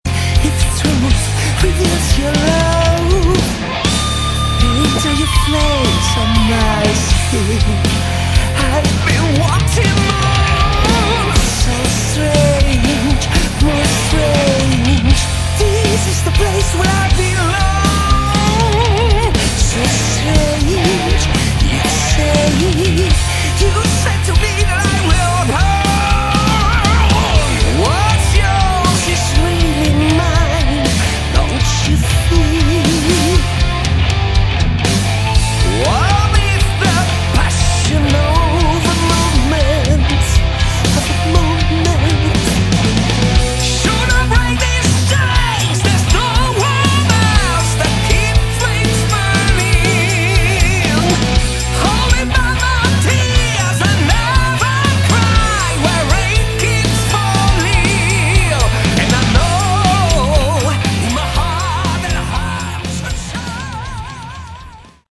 Category: Melodic/Power Metal